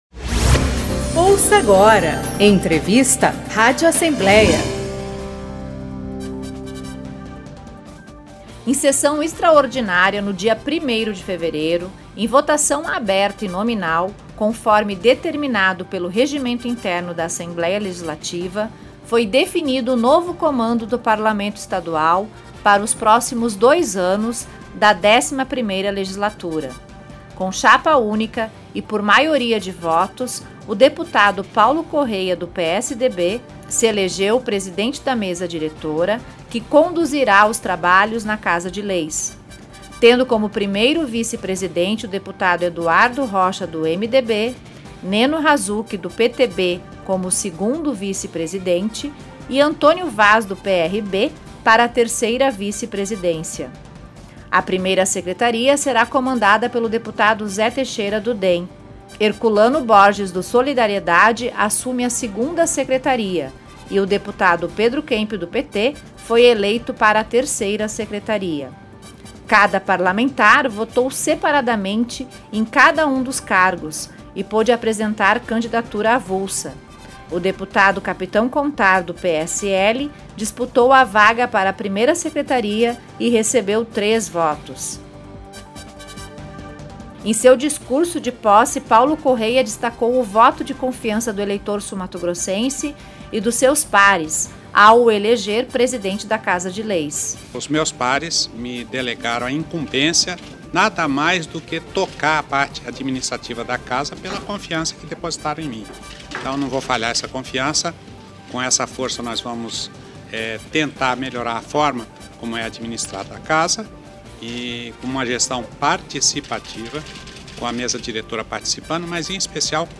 O novo comando da Assembleia Legislativa de Mato Grosso do Sul foi definido nesta sexta-feira (1), em sessão extraordinária, com votação entre os 24 deputados. A disputa ocorreu com chapa única e, por maioria de votos, Paulo Corrêa (PSDB) foi eleito o novo presidente, acompanhe o boletim da cobertura.